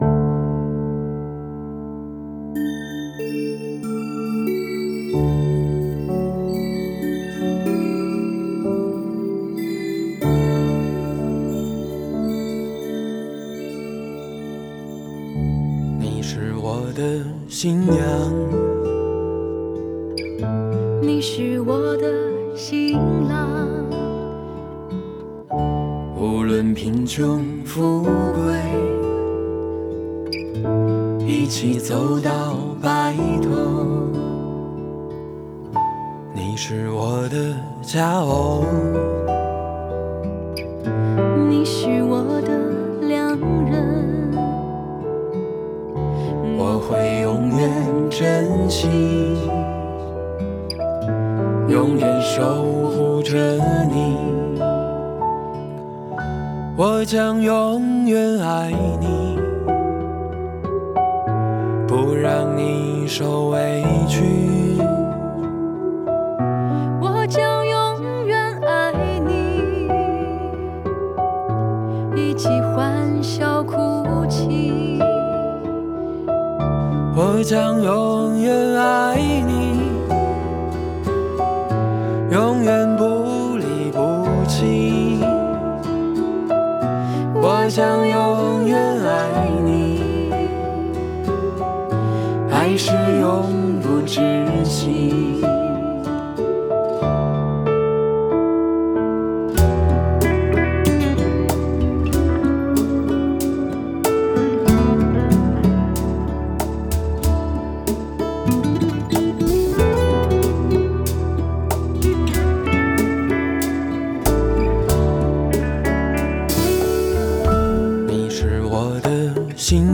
制作：Ekklesia国度12领导力 HAKA三层天祷告框架： 敬拜音乐：再次将我更新 默想经文及宣告： 当进入第四步，也用 1189 经文默想的方式，来选择 被圣灵感动要默想或者宣告的经文，来为五执华人国 际团队，来为家庭，来为自己进行经文的场景中默想，祷告，宣告，突破！ HAKA祷告敬拜MP3 启示性祷告： 持续祷告： 祈求神的旨意成就在我们身上，启示性恩膏临到，明白身份，听到呼召，进入命定！